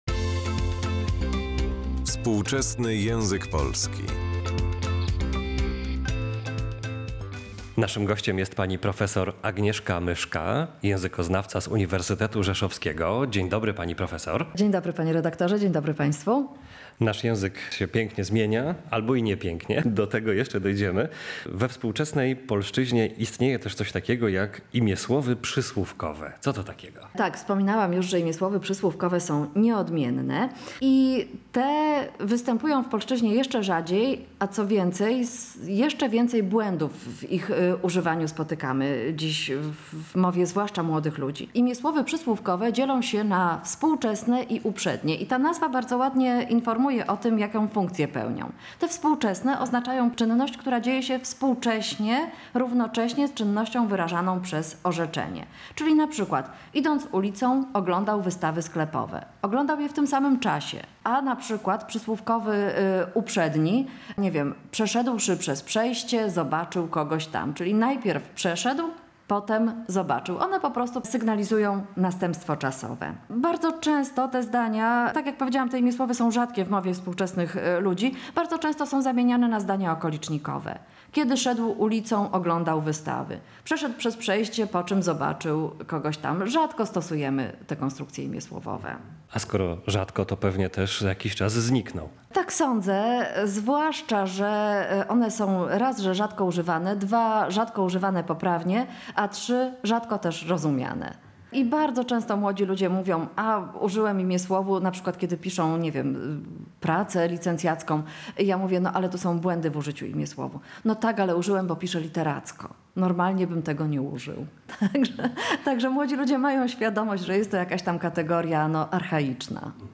Warto posłuchać rozmowy